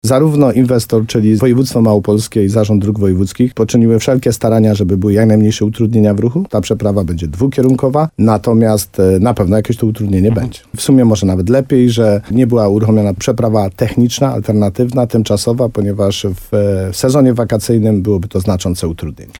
Jak zapewniał w programie Słowo za Słowo w radiu RDN Nowy Sącz wójt gminy Ochotnica Dolna Tadeusz Królczyk, prace będą wykonywane tak, aby sama trasa była możliwie, jak najbardziej przejezdna.